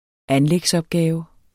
Udtale [ ˈanlεgs- ]